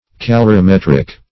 calorimetric - definition of calorimetric - synonyms, pronunciation, spelling from Free Dictionary
\Ca*lor`i*met"ric\